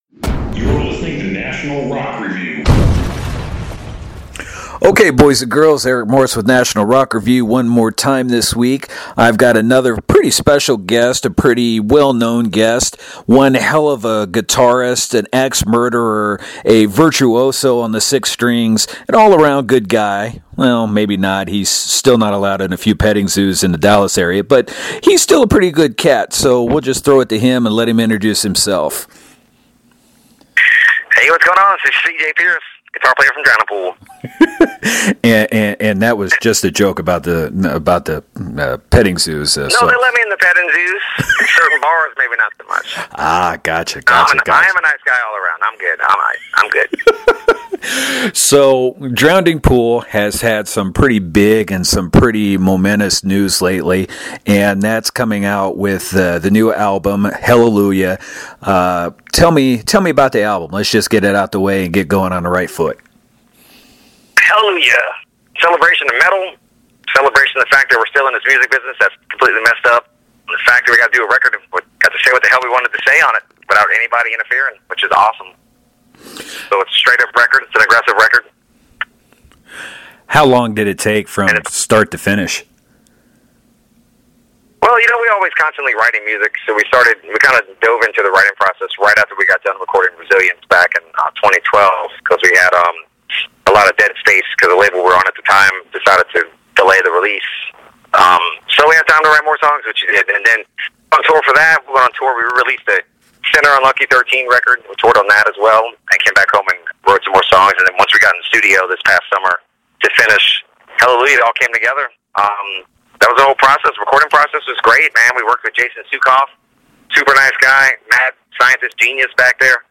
But you’ll have to listen to the interview with CJ to find out what it is.